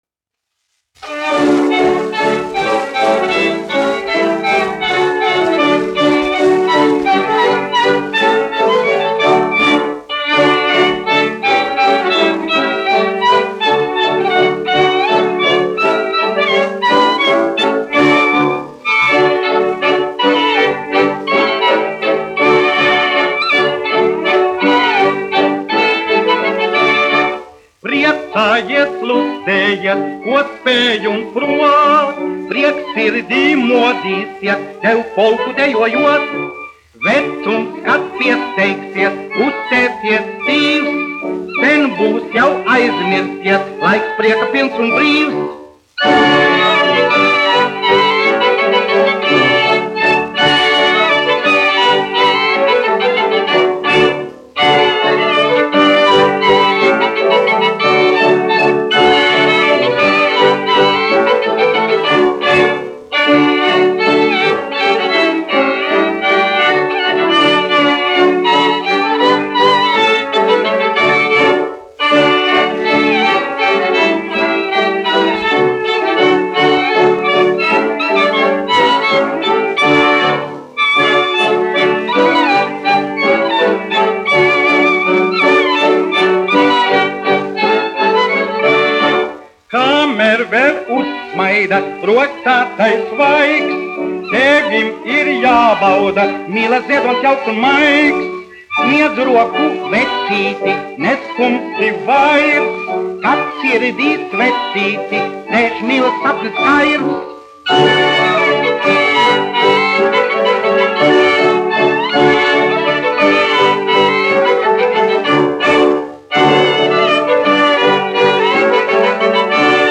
1 skpl. : analogs, 78 apgr/min, mono ; 25 cm
Polkas
Populārā mūzika
Skaņuplate